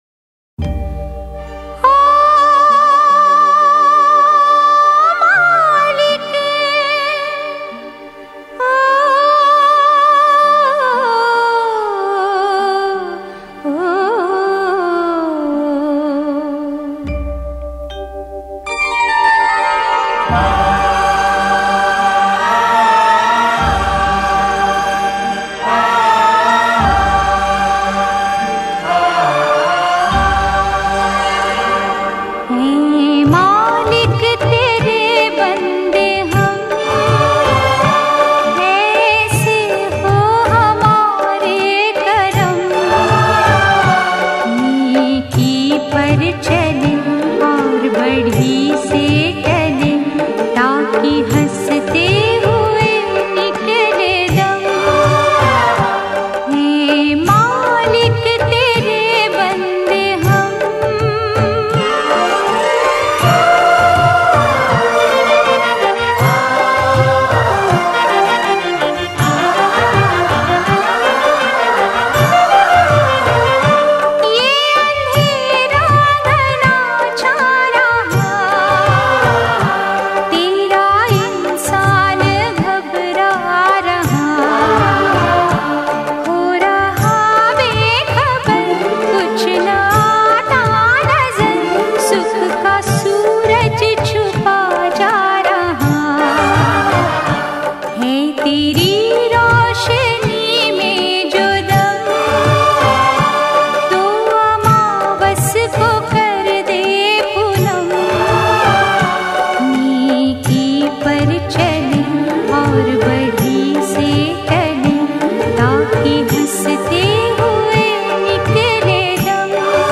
male